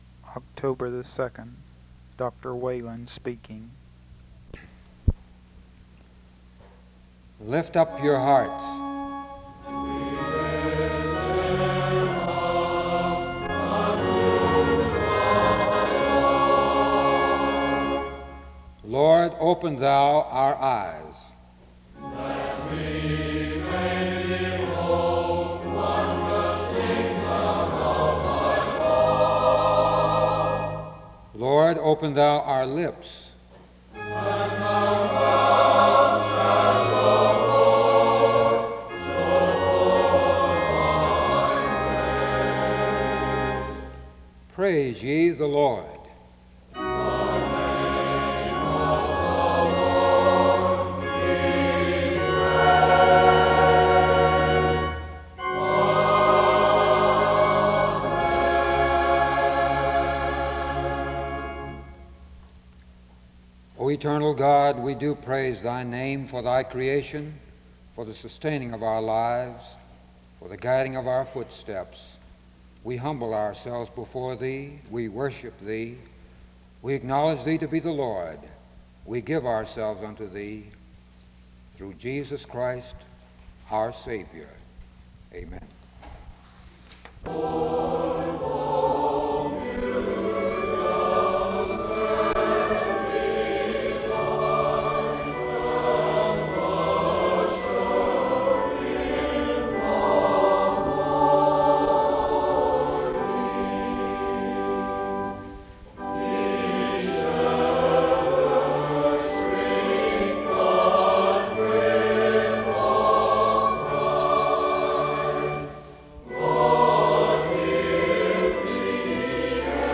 The service begins with a song from 0:00-1:01. A word of prayer is offered and another song is sung from 1:03-2:52. A second prayer is given from 3:10-4:22.
SEBTS Chapel and Special Event Recordings SEBTS Chapel and Special Event Recordings